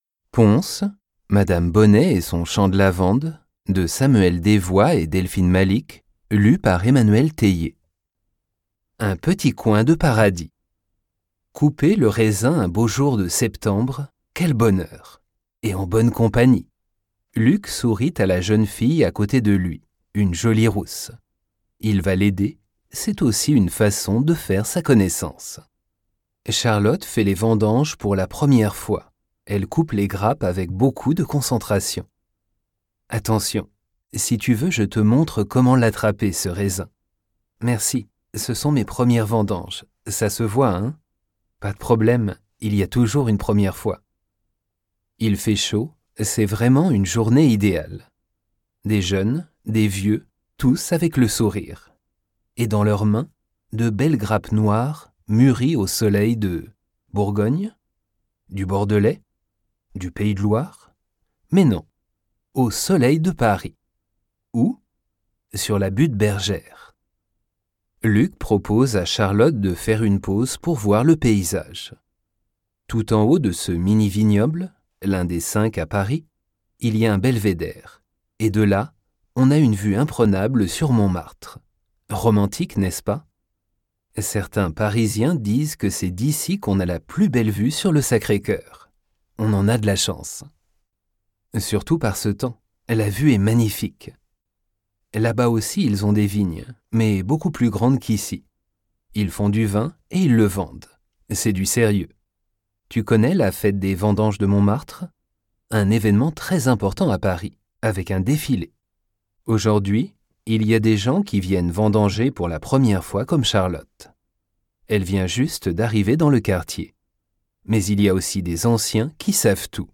20 landestypische Hörgeschichten zum Französischlernen (A1-A2)
Mit diesen abwechslungsreichen Kurzgeschichten frischen Sie ganz entspannt Ihre Französischkenntnisse auf. Echte Franzosen hören und perfekt nachsprechen – muttersprachliche Sprecher sorgen für den letzten Schliff.